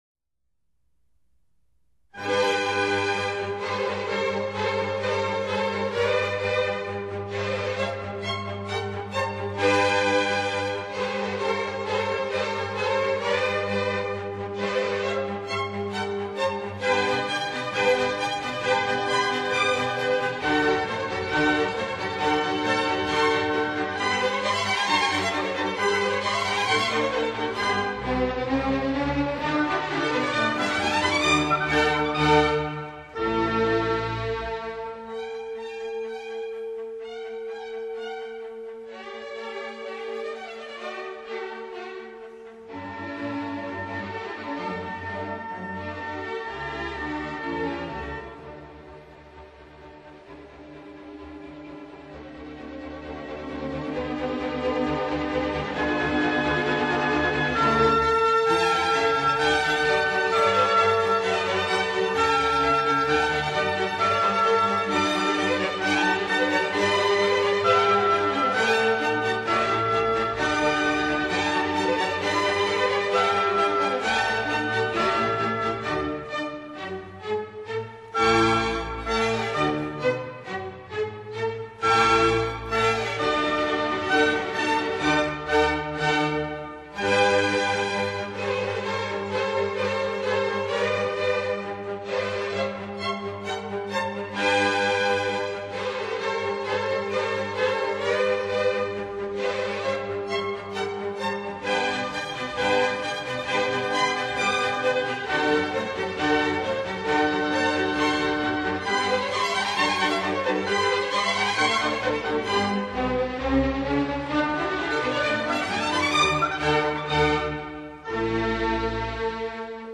虽然听上去有些过于厚重，但却十分大气